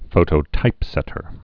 (fōtō-tīpsĕtər)